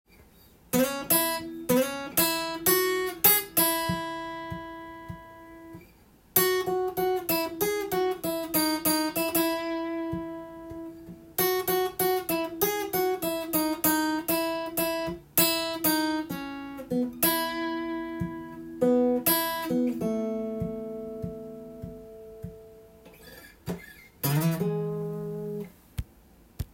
をメロディーTAB譜にしてみました。